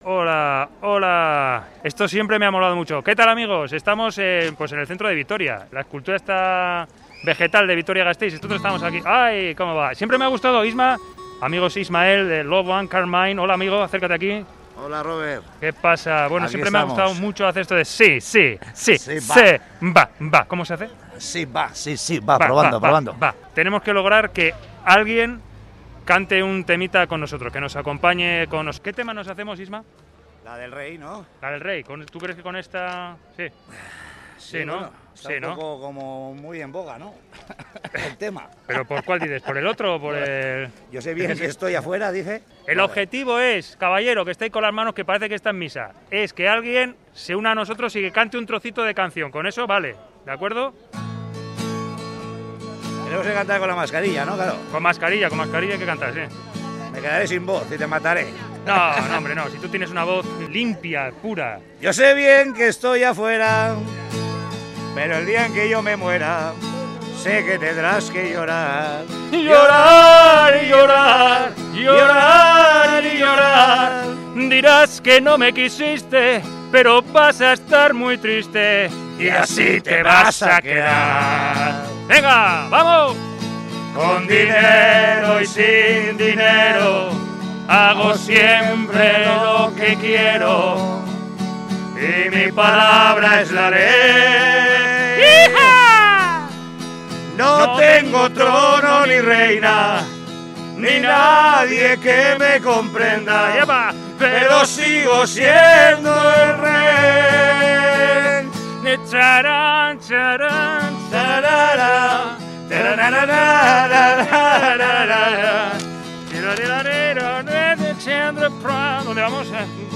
Audio: Reportaje: Agitando las calles de Vitoria (vol.I)
Audio: Salimos a las calles de Gasteiz con el objetivo de que las gentes se unan a nuestro canturreo.